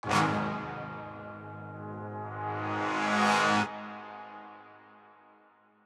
TM-88 Hit #04.wav